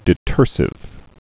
(dĭ-tûrsĭv, -zĭv)